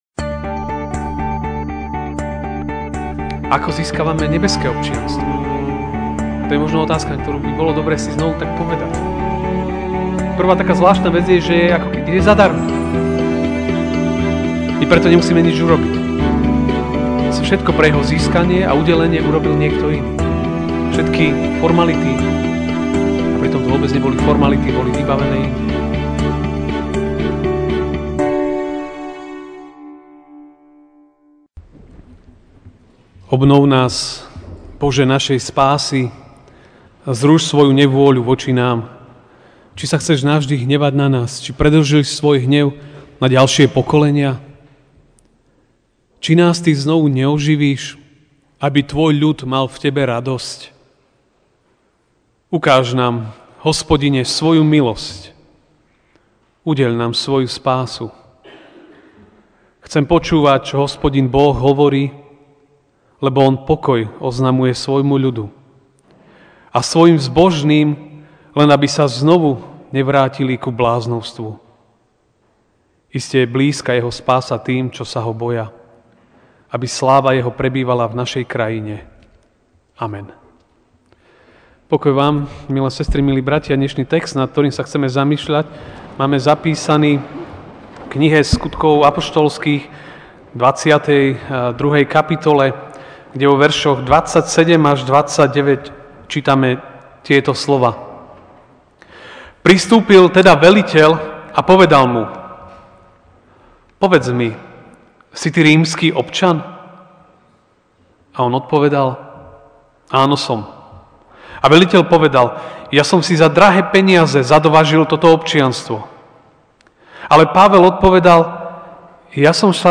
Ranná kázeň: Cena občianstva (Sk 22, 27-29)Pristúpil teda veliteľ a povedal mu:Povedz mi, si ty rímsky občan?